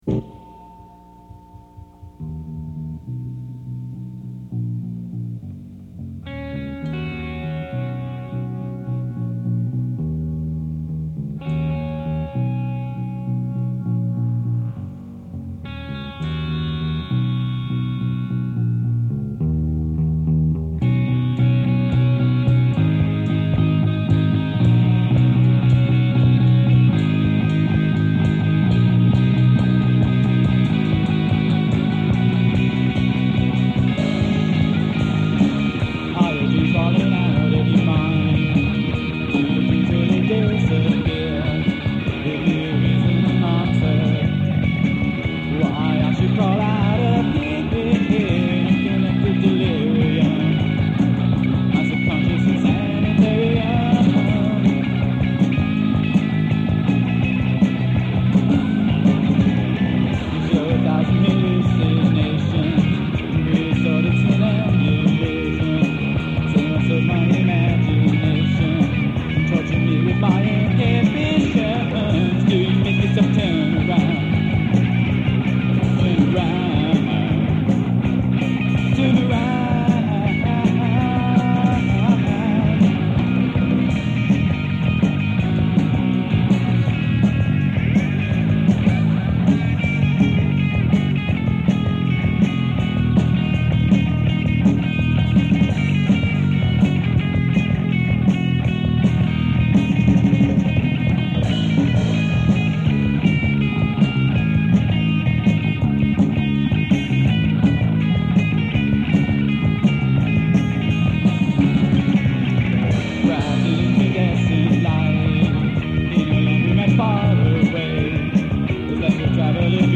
psychedelically minded garage-punk trio
was recorded in June 1985 at Mira Costa
bass and vocals
guitar
drums